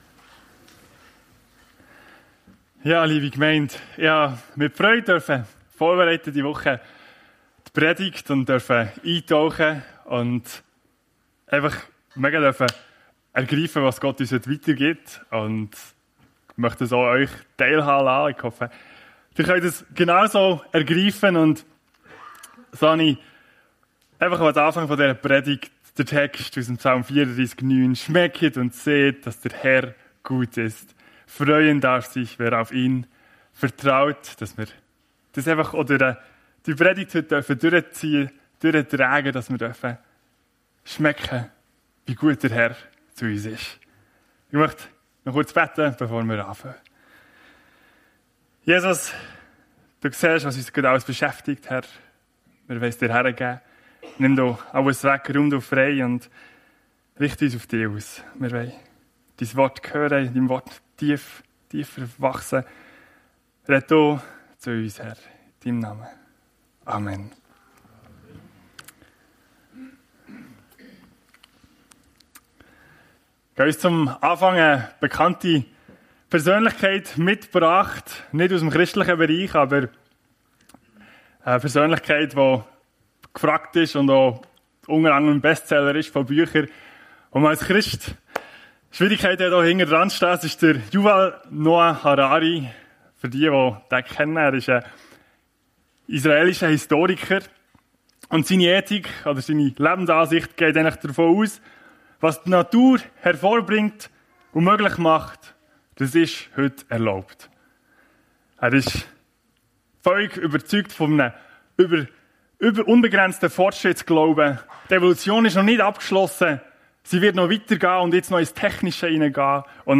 Und trotzdem liebt Er dich - Psalm 139 ~ FEG Sumiswald - Predigten Podcast